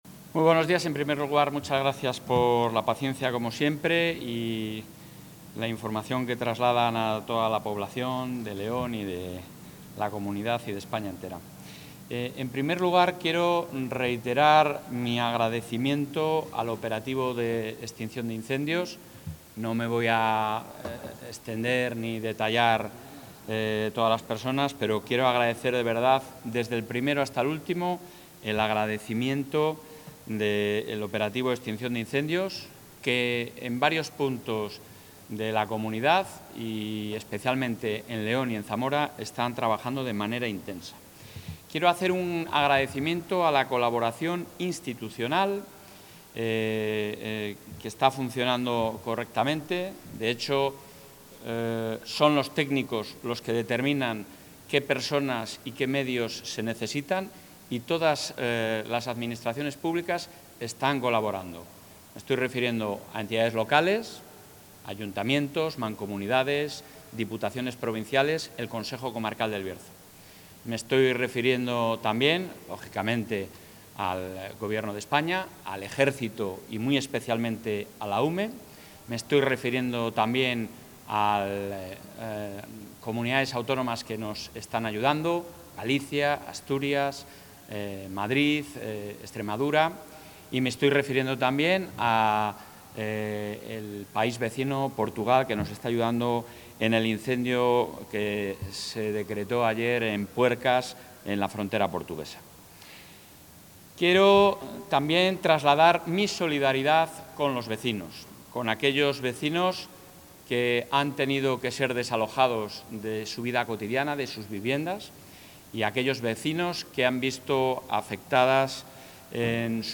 El presidente de la Junta asiste a la reunión del CECOPI autonómico en León
Intervención del presidente de la Junta.